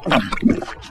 • wet gulp.wav
wet_gulp_e6z.wav